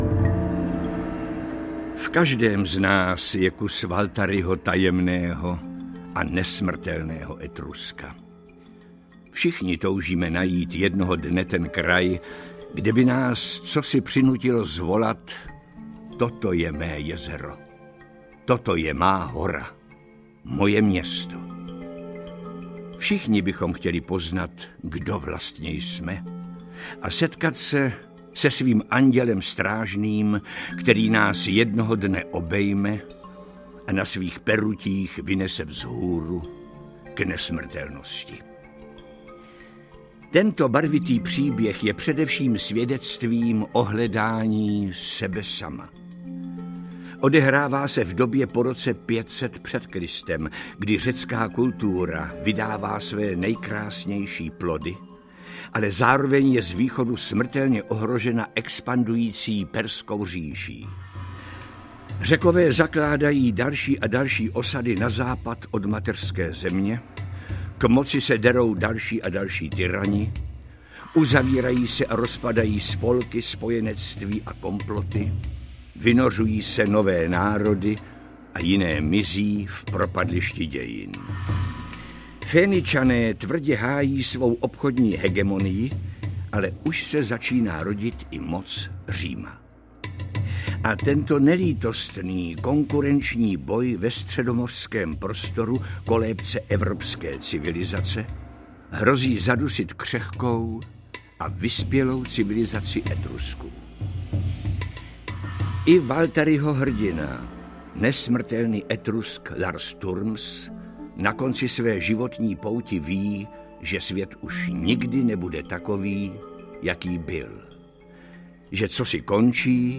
Tajemný Etrusk audiokniha
Ukázka z knihy
tajemny-etrusk-audiokniha